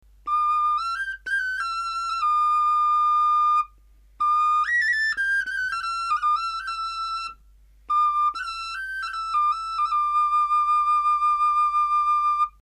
三穴小管